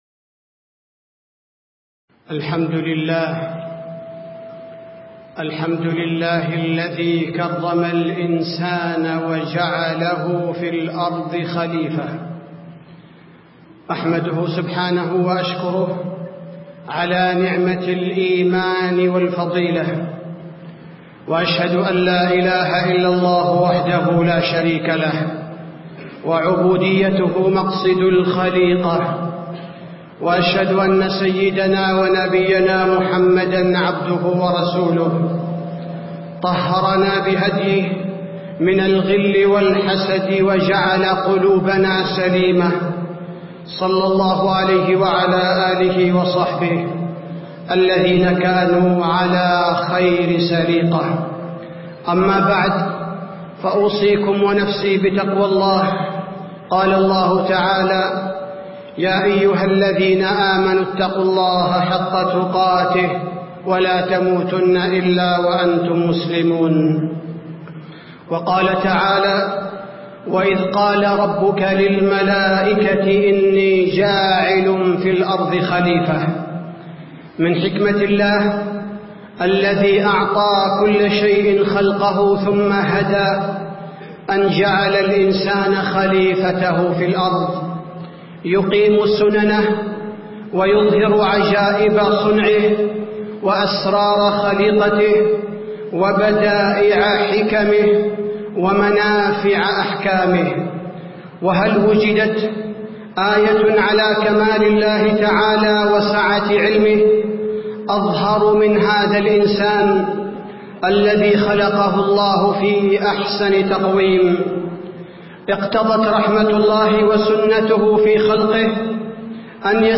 تاريخ النشر ١٠ جمادى الأولى ١٤٣٧ هـ المكان: المسجد النبوي الشيخ: فضيلة الشيخ عبدالباري الثبيتي فضيلة الشيخ عبدالباري الثبيتي رسالة المسلم في الحياة